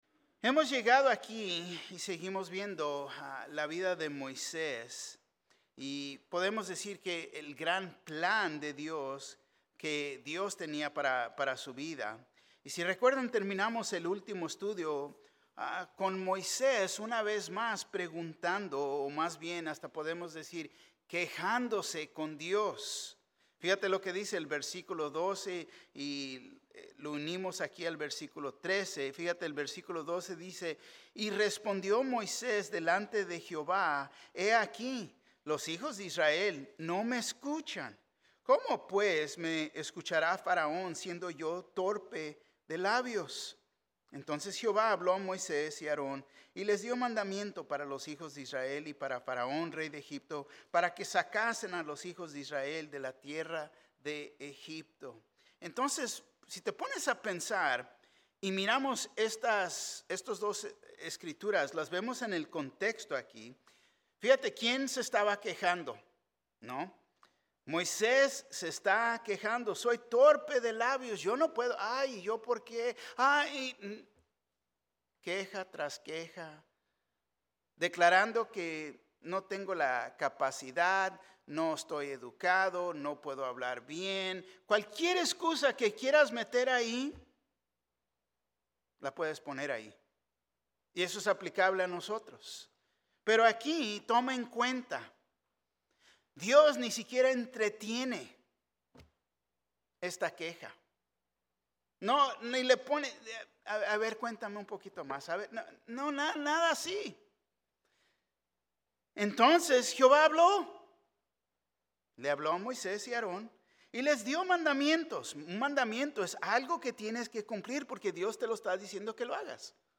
Un mensaje de la serie "Estudios Tématicos."